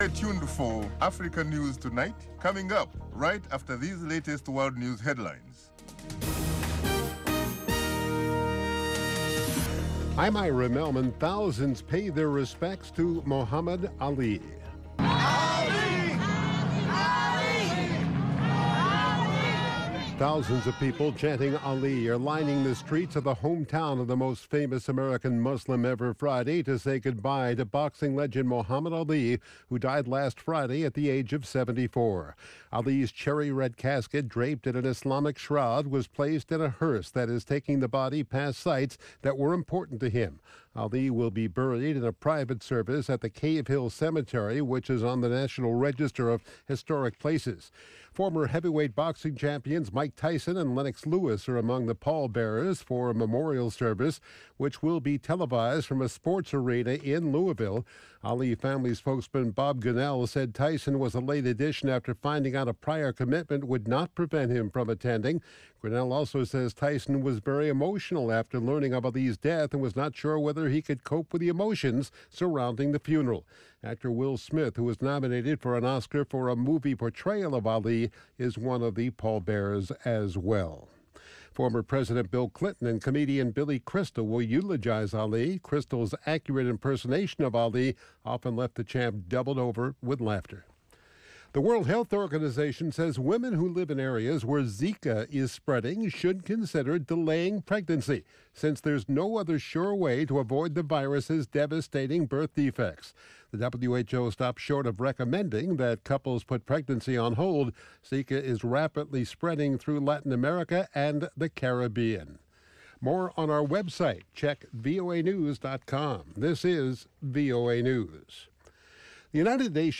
2 Minute Newscast